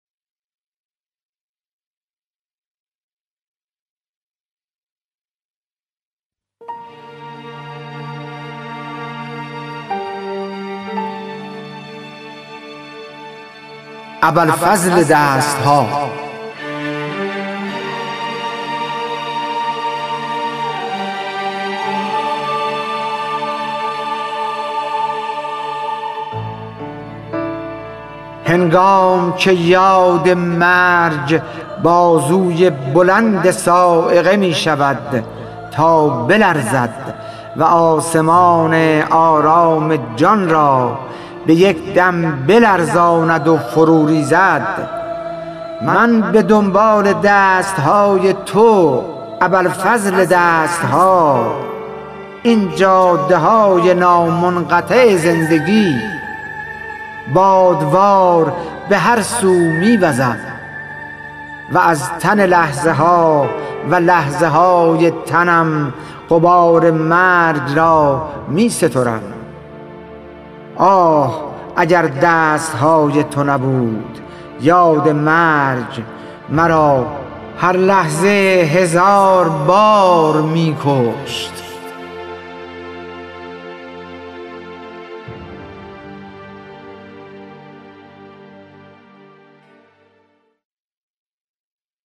خوانش شعر سپید عاشورایی / ۲